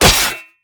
metal2.ogg